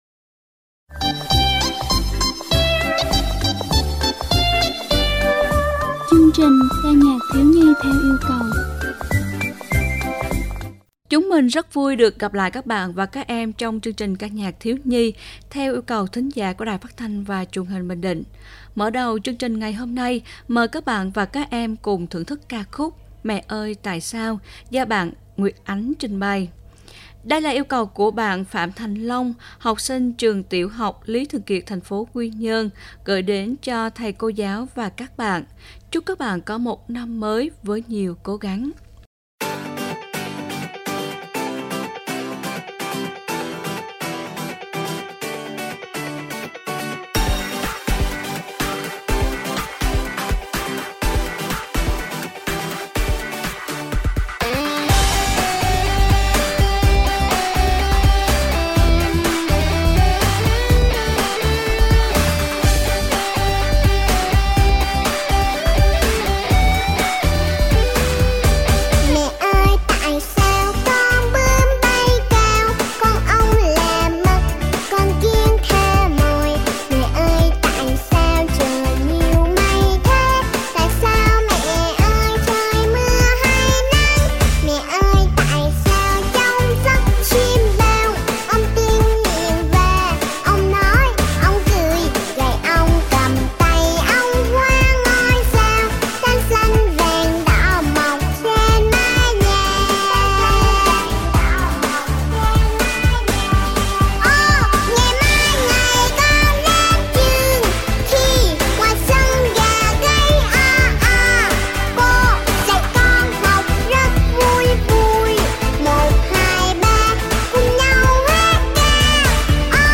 27-8-ca-nhac-thieu-nhi_1.mp3